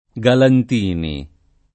[ g alant & ni ]